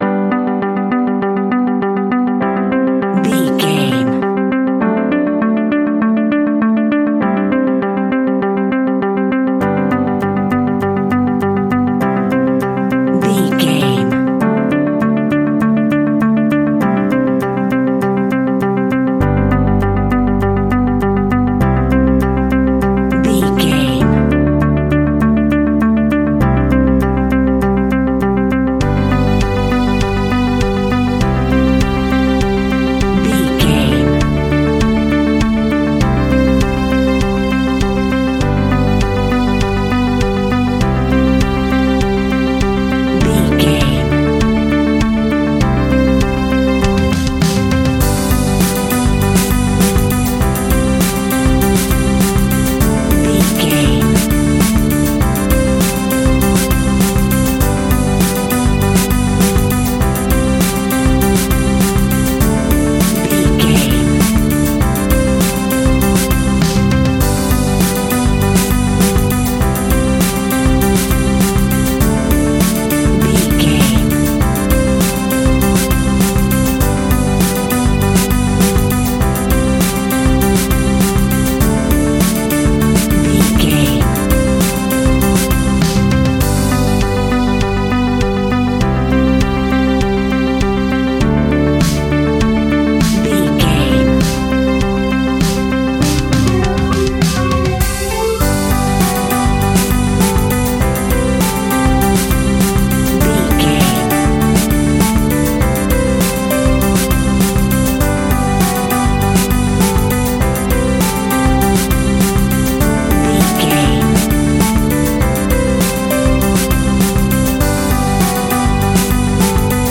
Ionian/Major
pop rock
energetic
uplifting
catchy
upbeat
acoustic guitar
electric guitar
drums
piano
organ
electric piano
bass guitar